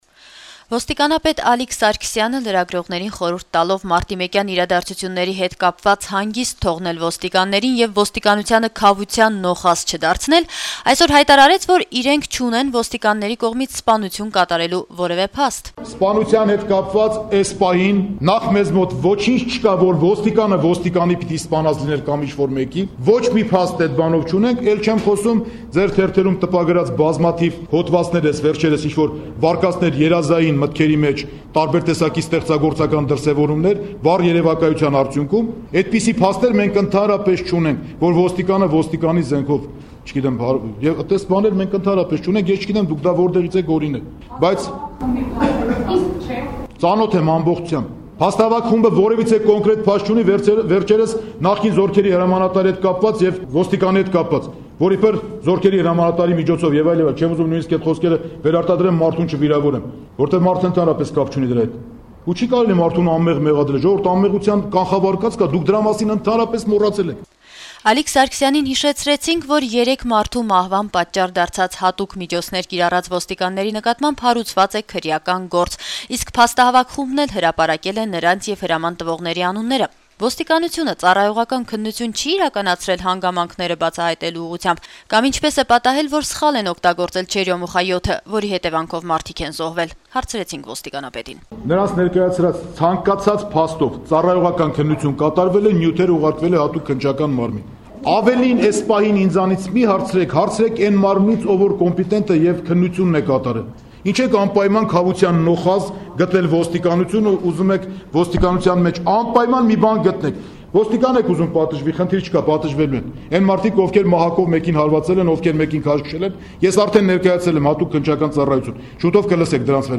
Ալիկ Սարգսյանի ասուլիսը